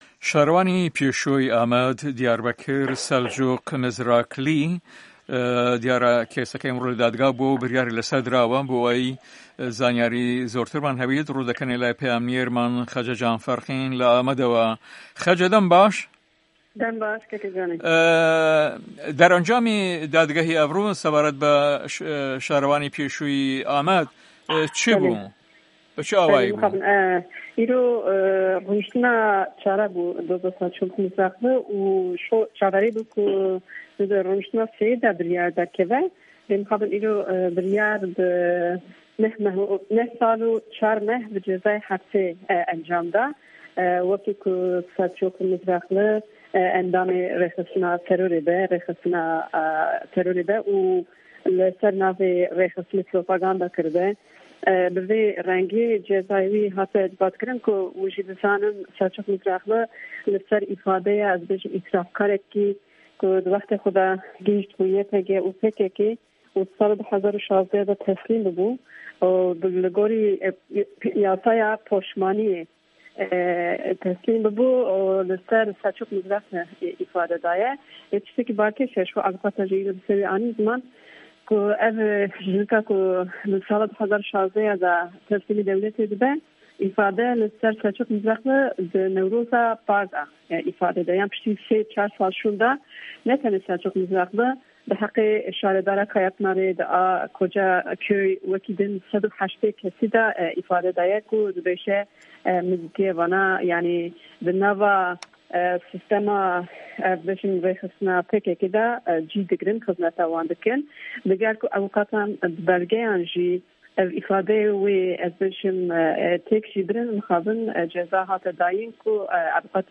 Nûçegîhana me